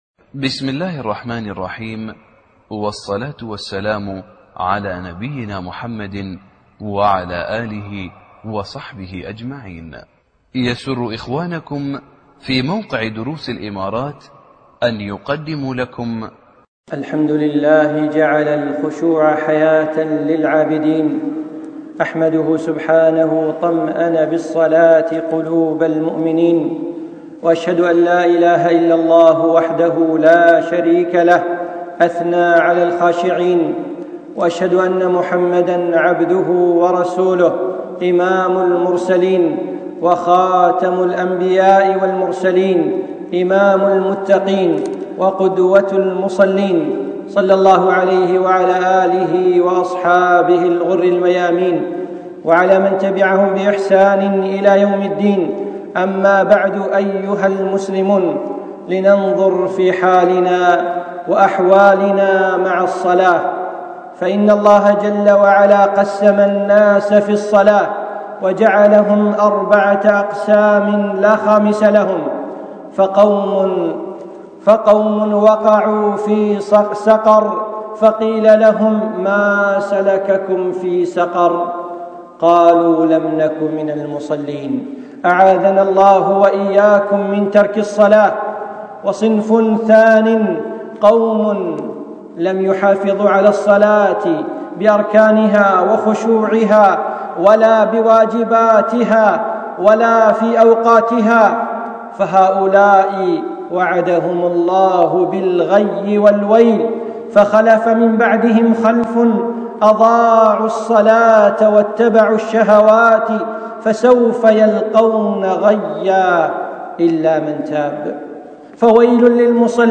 من خطب الشيخ في دولة الإمارات